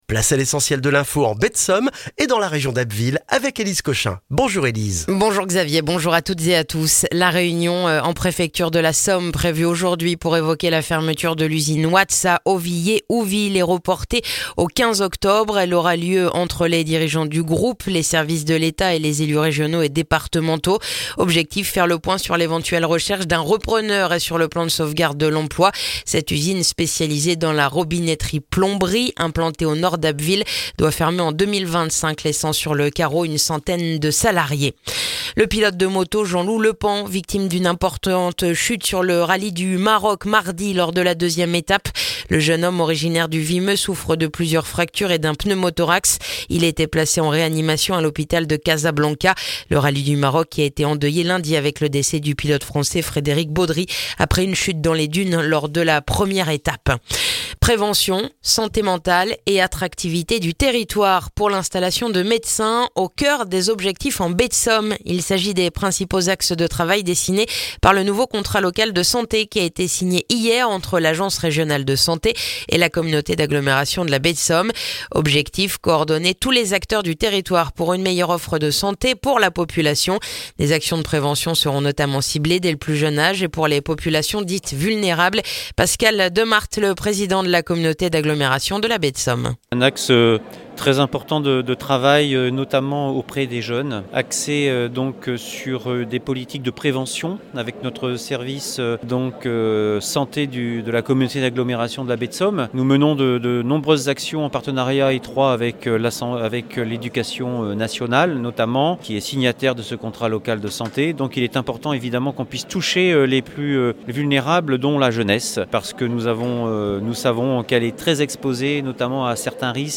Le journal du jeudi 10 octobre en Baie de Somme et dans la région d'Abbeville